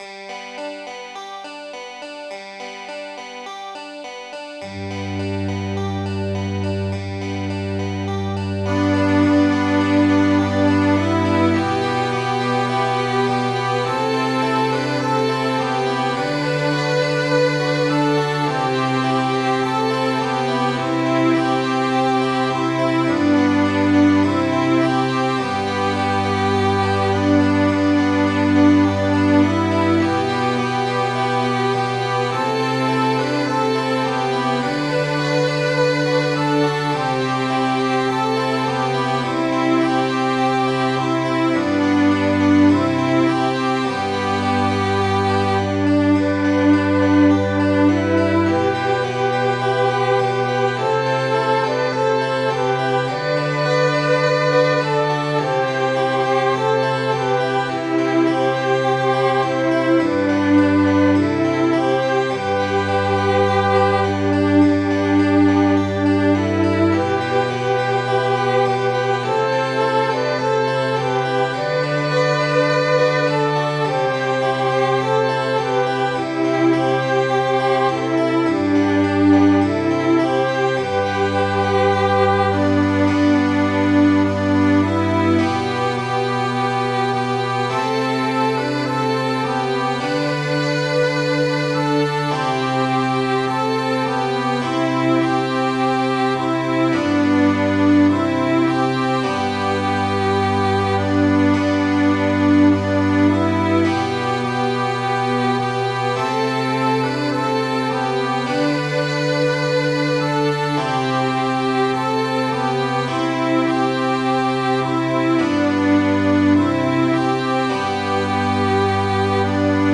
Midi File, Lyrics and Information to The Cruel War